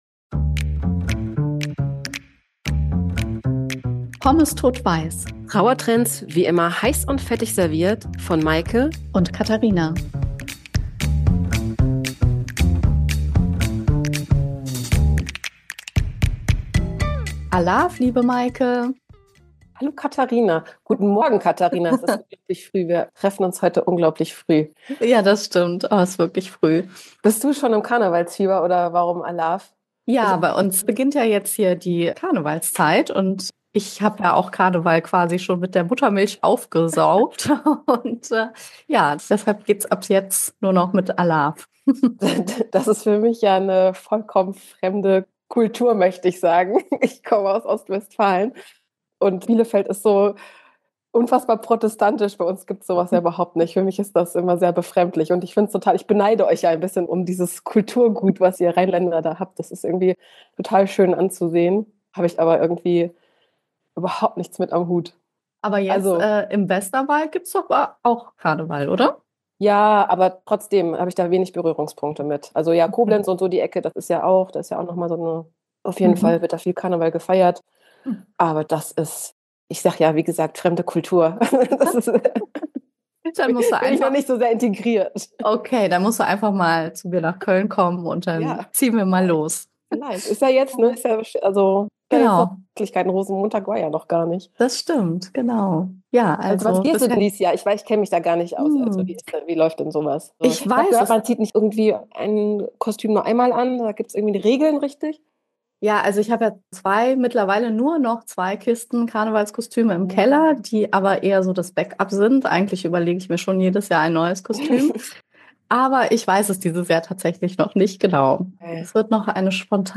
Zwei Trauerbegleiterinnen, die finden dass Leichtigkeit für Trauer wie das Salz für Pommes ist.